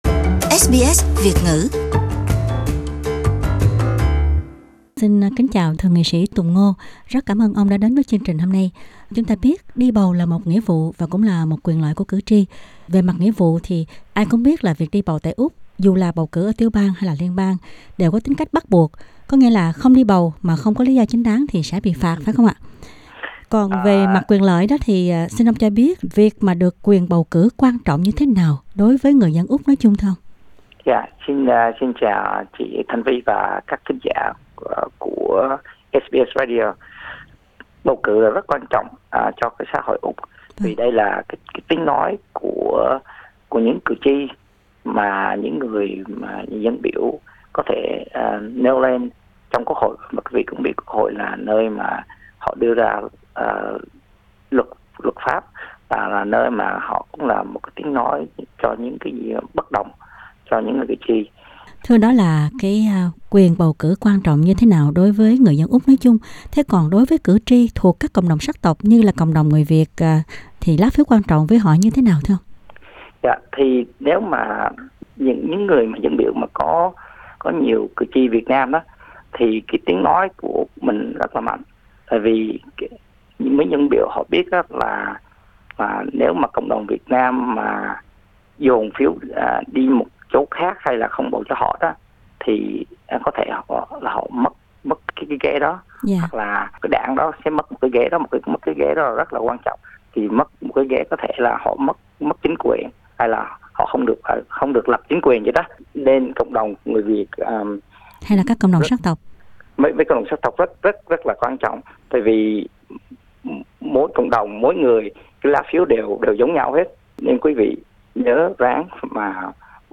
Phỏng vấn Thượng Nghị sĩ Tùng Ngô Ngày thứ bảy, 24/11/2018 cuối tuần này là ngày cử tri tại Victoria sẽ đi bầu nghị viện tiểu bang Victoria.